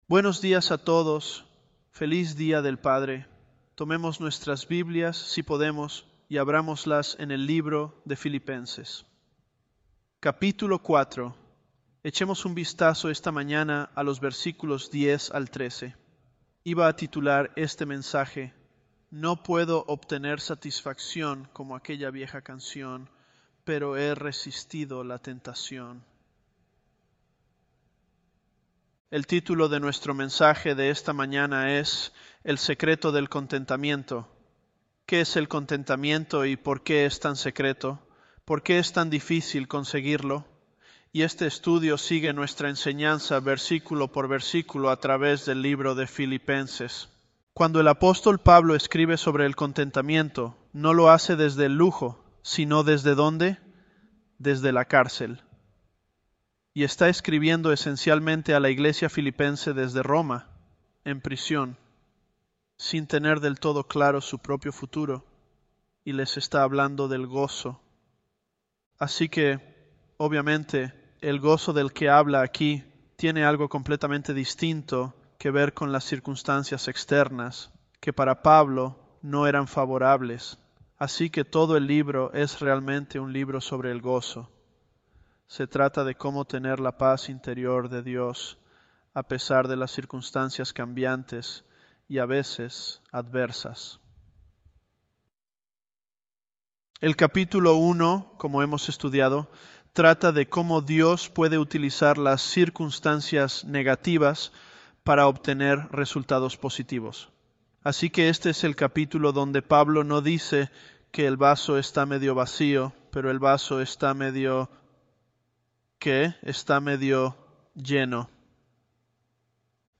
Sermons
Elevenlabs_Philippians012.mp3